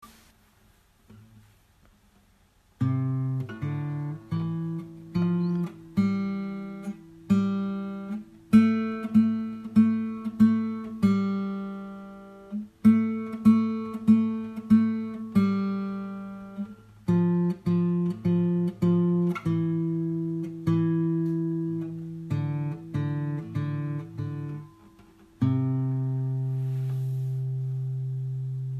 alle_meine_entchen_gitarre.mp3